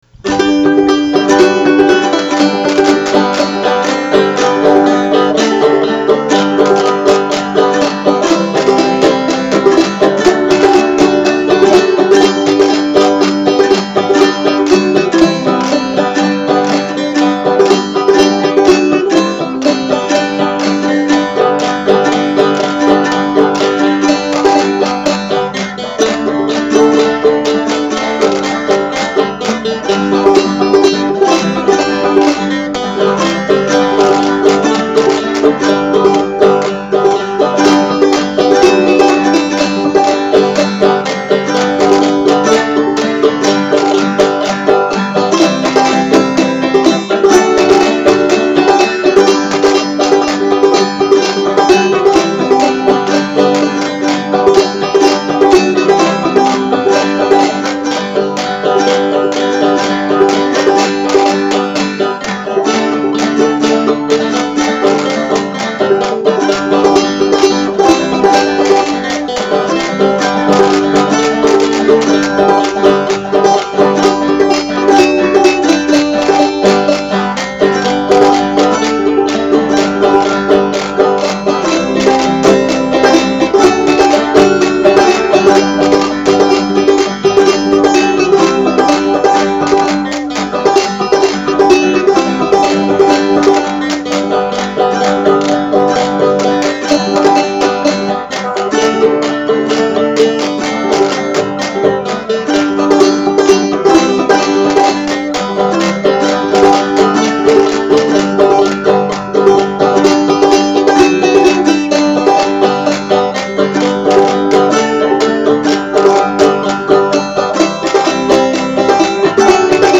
lead banjo, backup banjo, mandolin, washboard
flute
she ended up playing the bass part on her flute